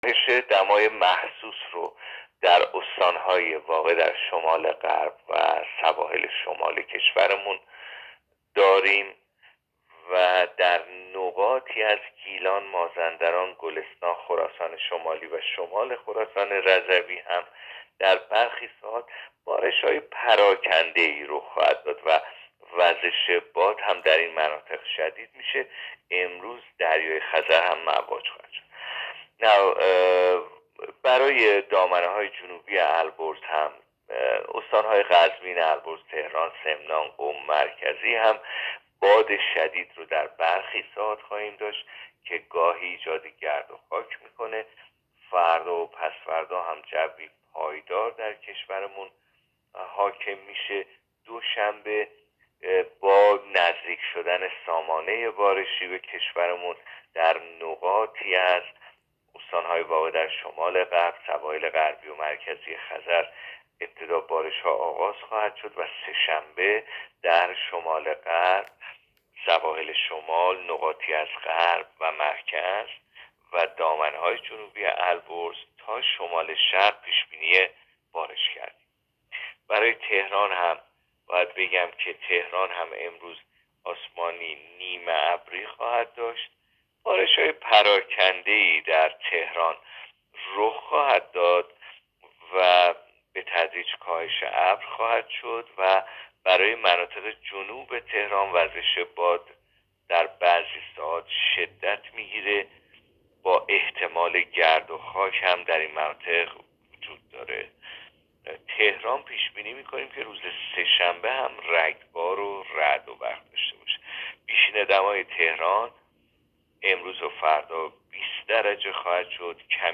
گزارش رادیو اینترنتی از آخرین وضعیت آب و هوای اول اسفند؛